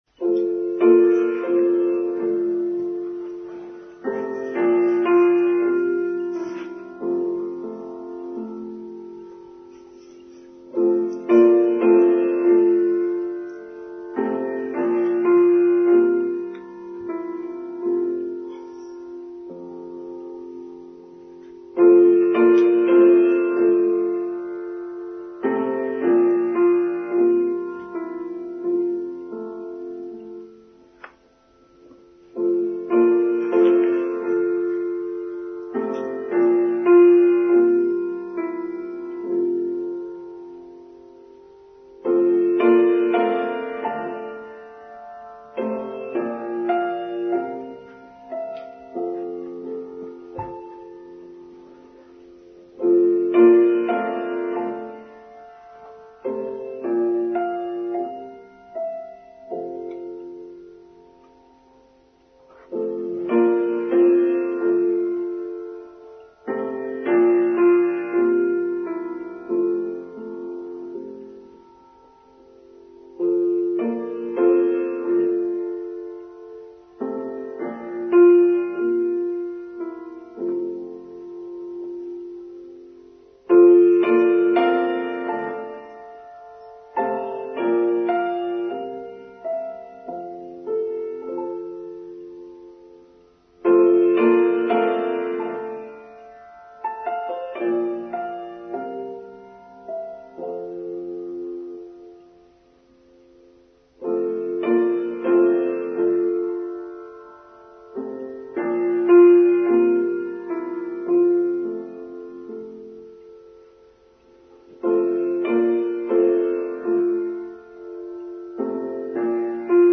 Prelude